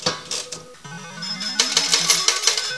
vegas sound